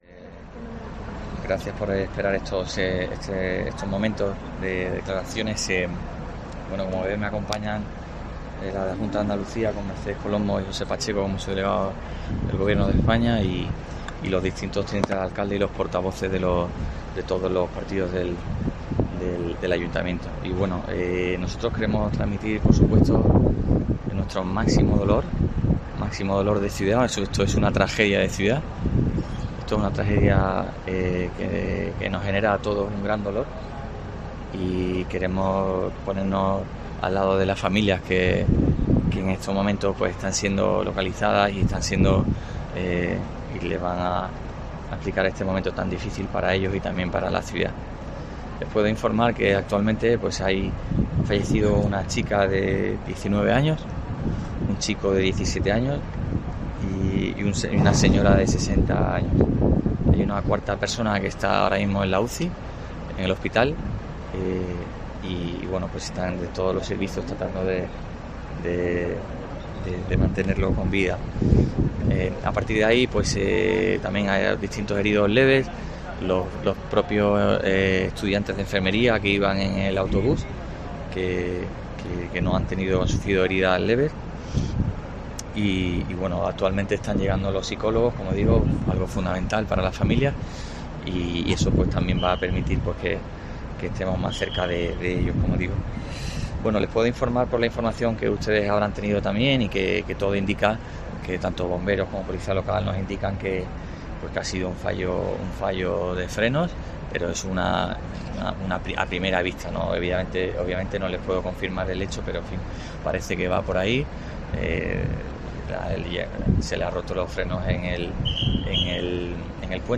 Bruno García, alcalde de Cádiz, habla del accidente ocurrido en Cádiz t